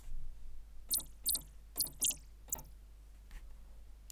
Звуки наведения на цель из "Игры В Кальмара"
Вложения Vital - BleepBloop.mp3 Vital - BleepBloop.mp3 163,1 KB · Просмотры: 1.053 Vital - ServoMotor.mp3 Vital - ServoMotor.mp3 188,4 KB · Просмотры: 1.077